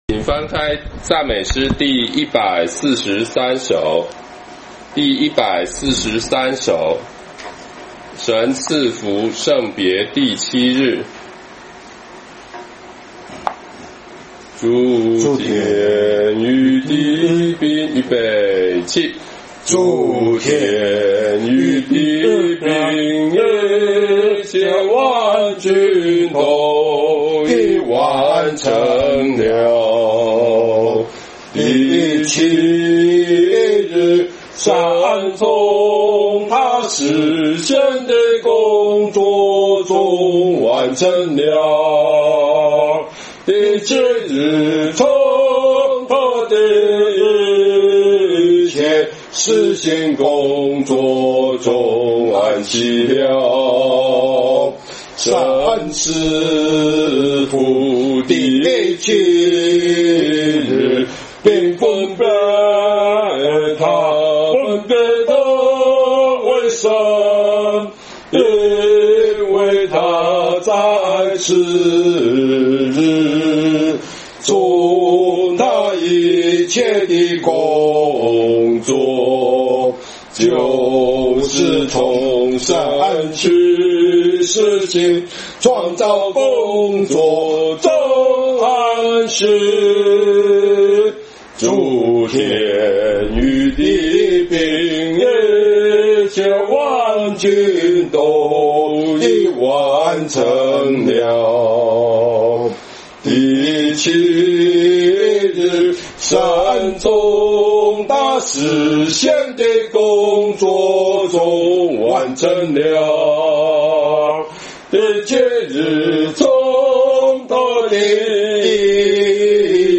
詩歌頌讚